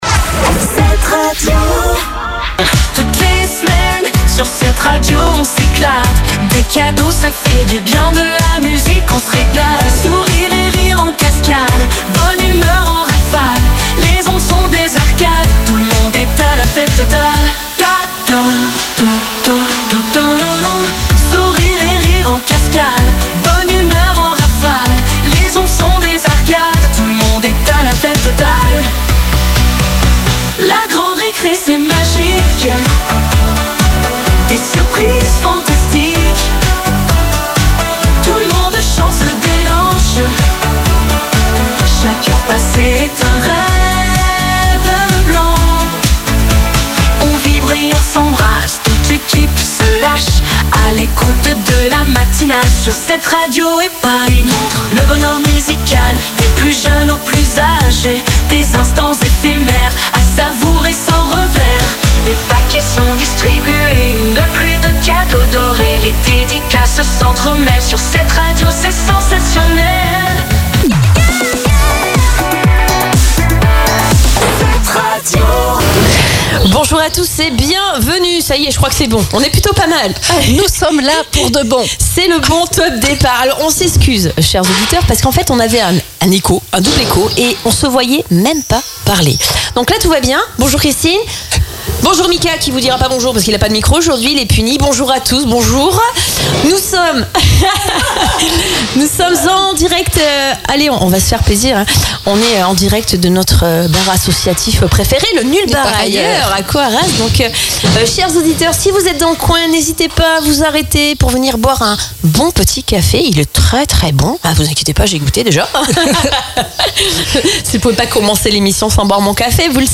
Podcast interview NUL BAR AILLEURS à Coarraze